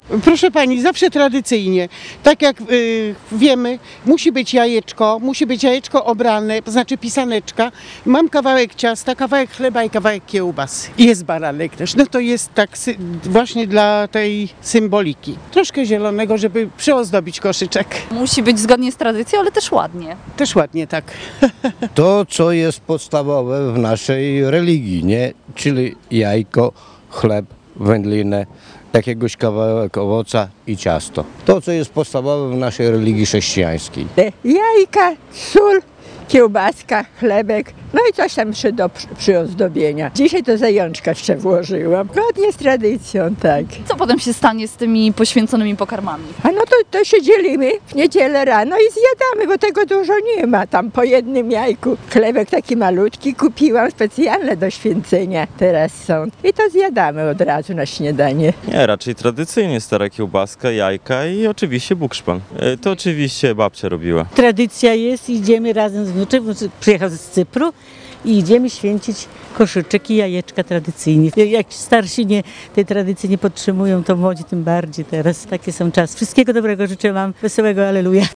Co wkładają do koszyczka mieszkańcy regionu? Reporterzy Radia 5 sprawdzali po uroczystości poświęcenia pokarmów w Kościele Najświętszego Serca Pana Jezusa w Ełku.
sonda-koszyczek.mp3